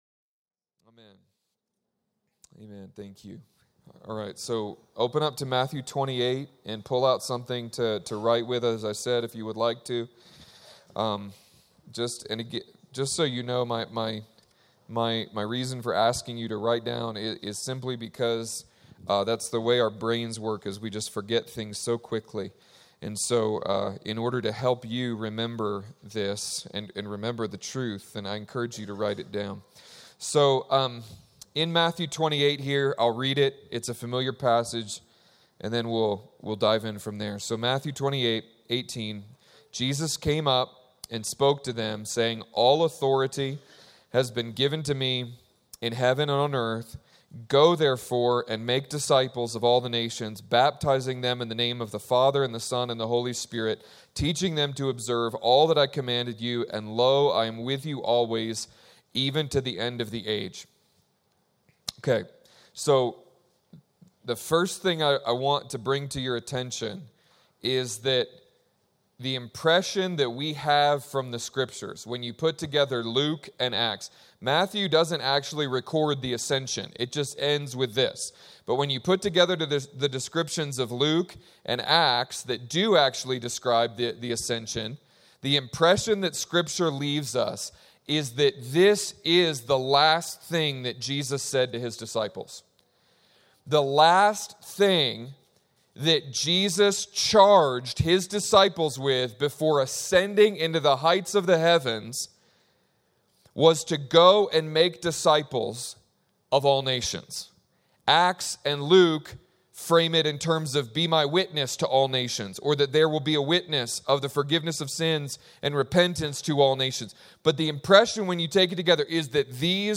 In the summer of 2016 student leaders from all over the country gathering at Mt. Hermon United around the dream of another student volunteer movement emerging out of America. Jesus desires all of the nations and He also desires all of our hearts. Discover the surprising place of extravagance in the Great Commission.